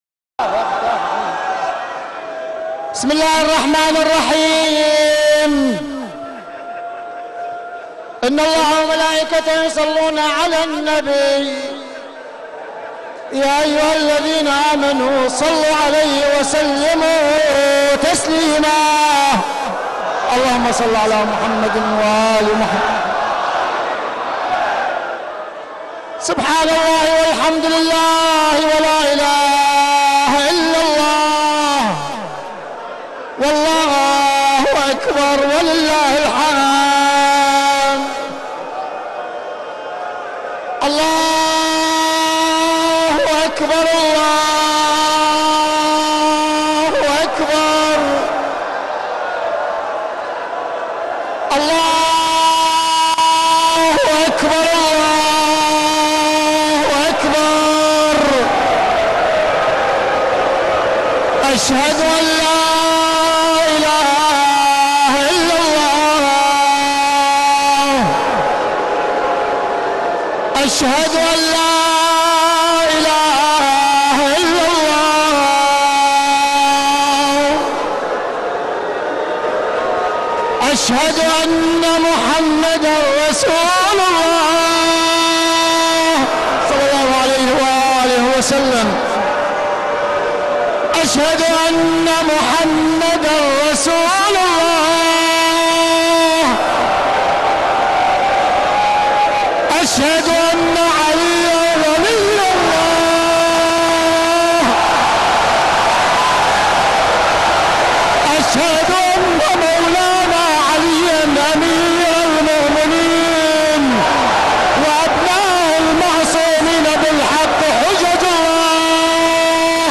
صوت: اذان‌ صبح‌ روز نوزدهم‌ رمضان، مسجدکوفه